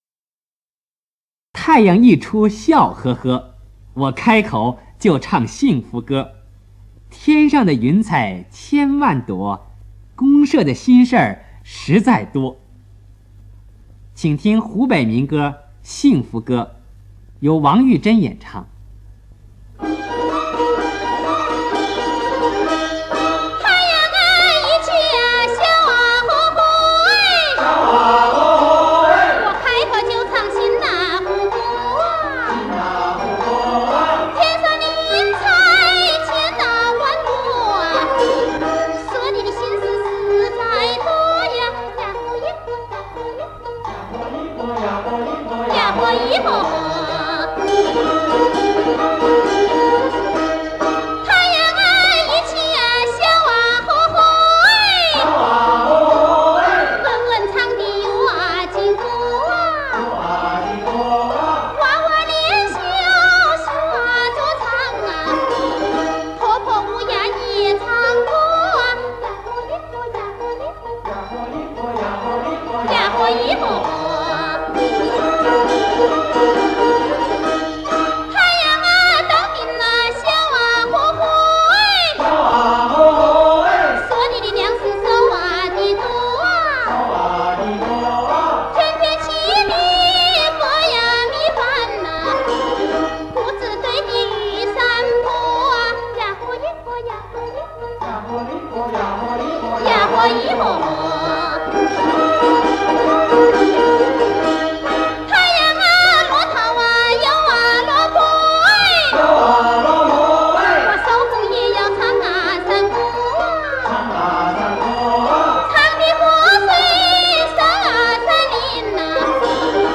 湖北天门民歌